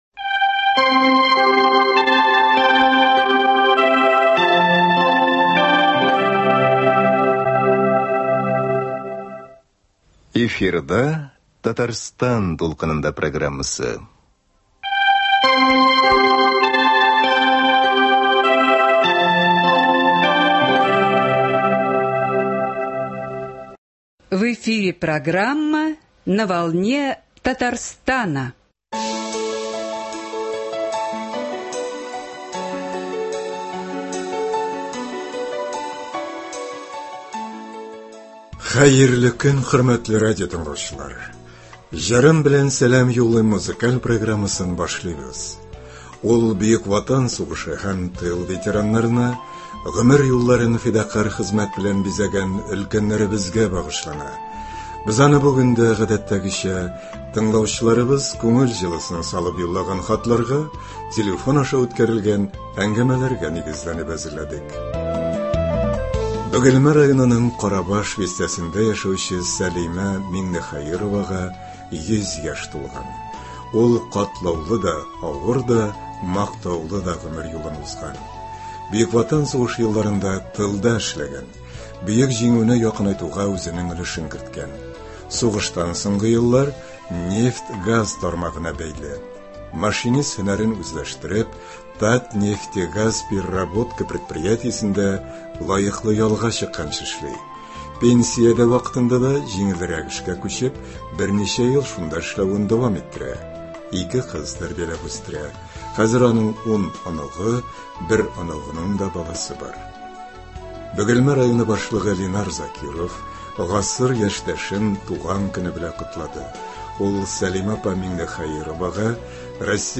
Ветераннар өчен музыкаль программа.